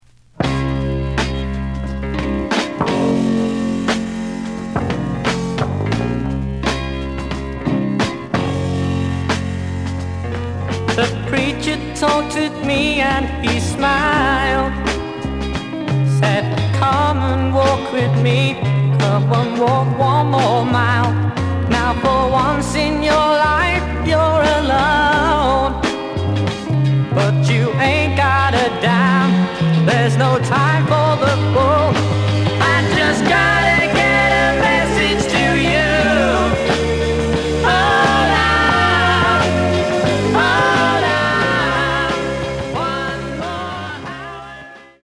The audio sample is recorded from the actual item.
●Format: 7 inch
●Genre: Rock / Pop